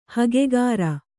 ♪ hagegāra